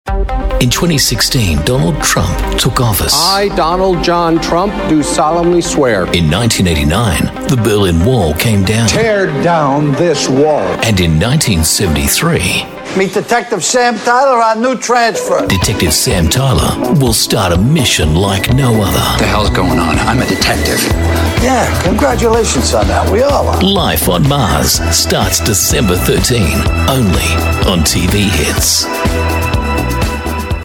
AU ENGLISH